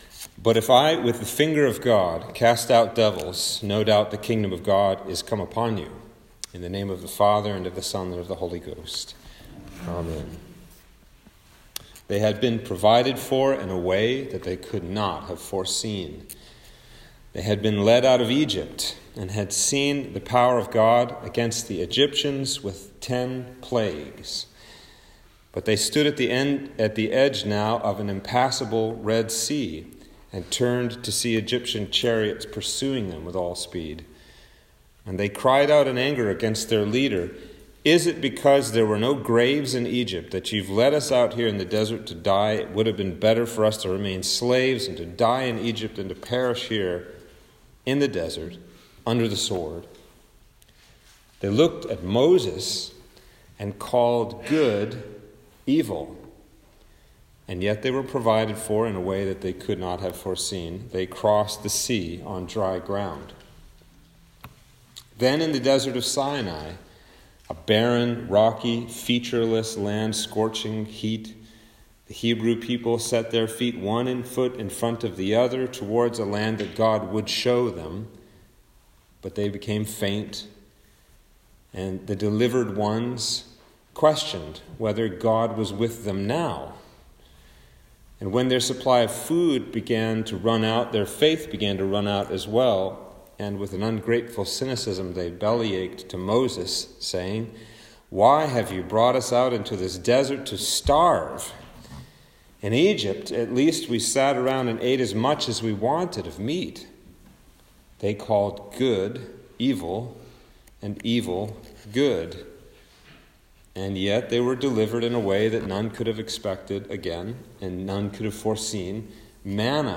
Sermon for Lent 3
Sermon-for-Lent-3-2021.m4a